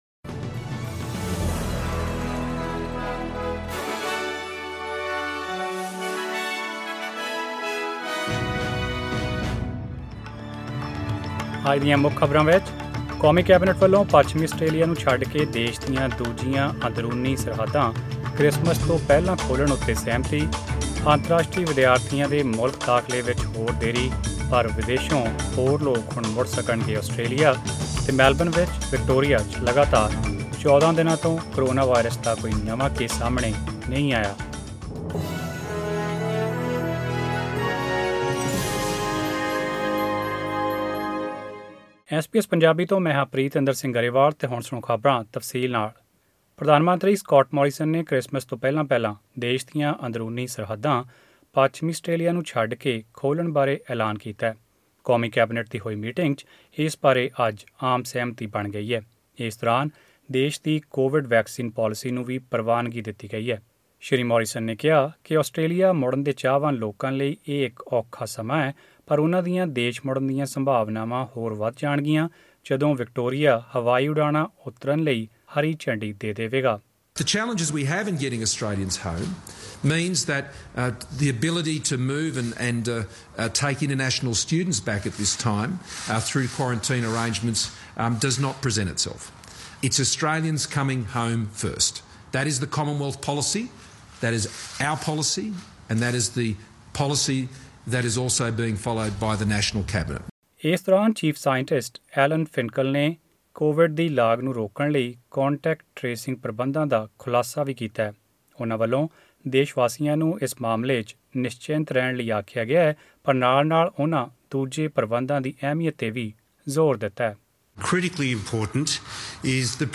Australian News in Punjabi: 13 November 2020